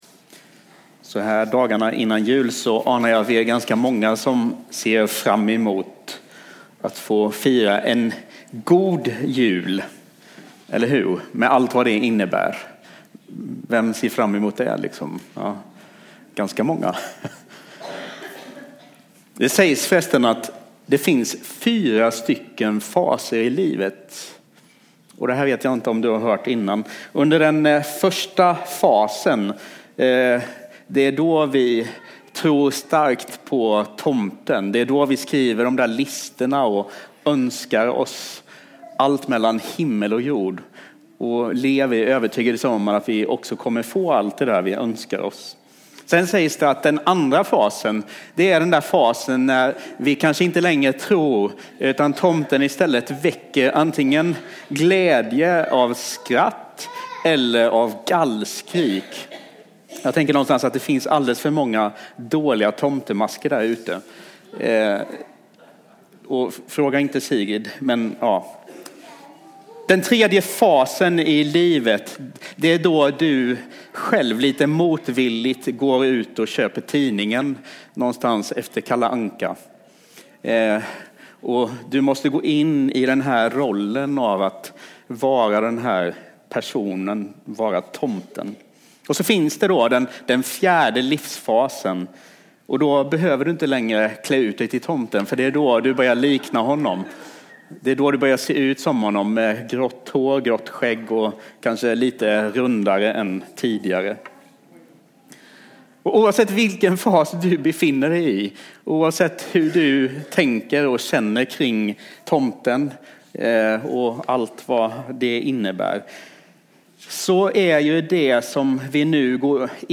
A predikan from the tema "Fristående HT 2017."